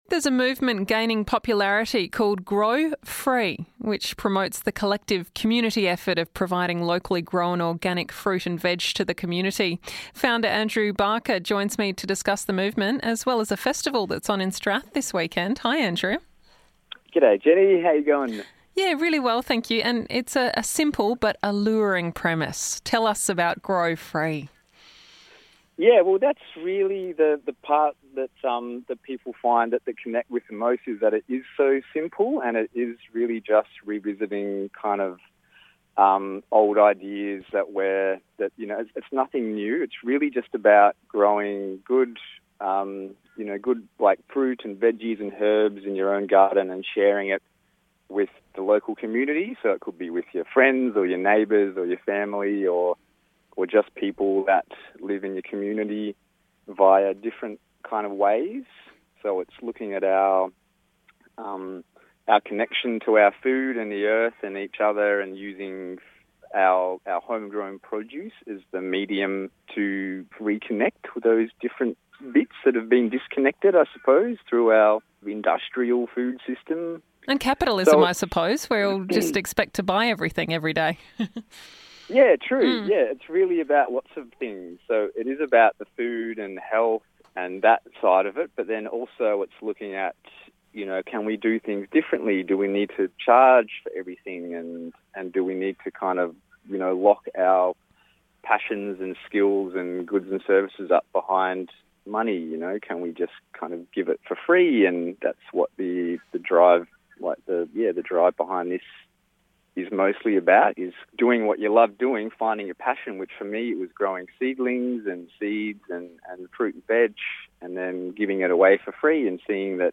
a chat